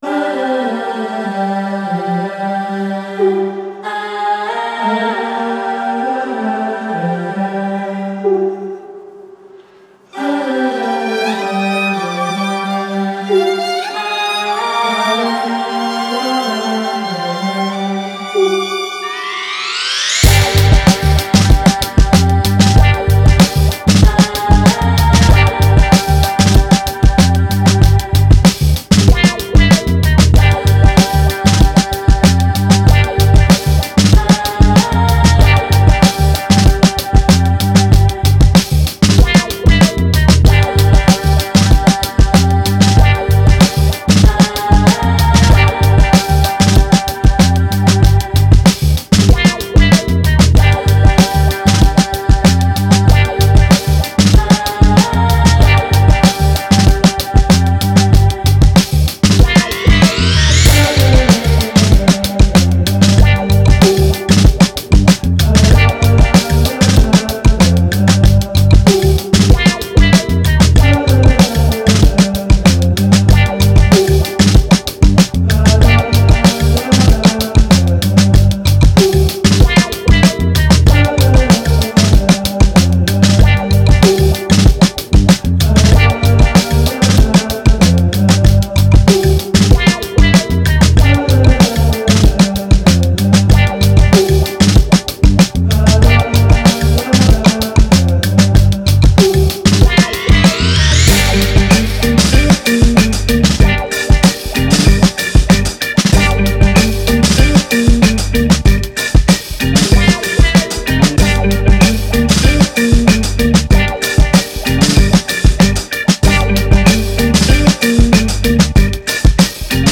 Breakbeat, Action, Upbeat